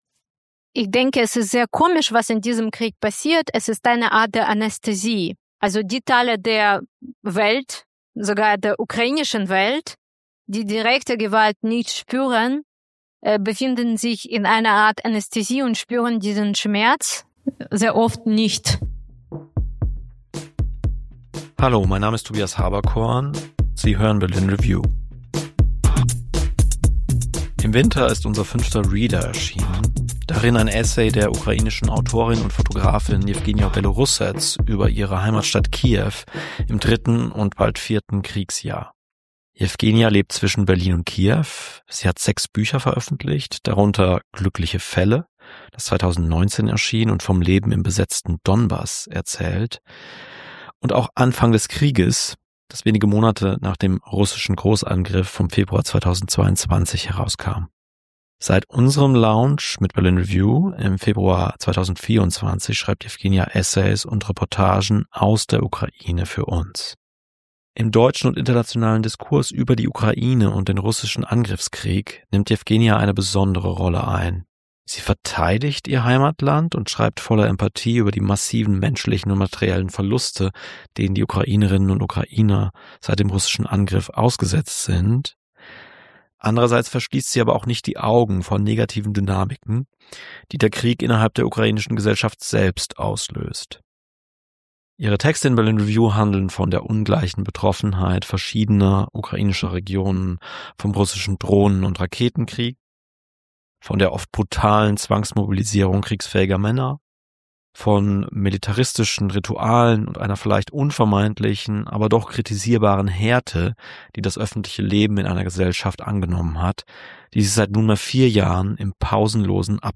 Vom Aushalten des Krieges: Ein Gespräch mit Yevgenia Belorusets ~ Berlin Review Audio Podcast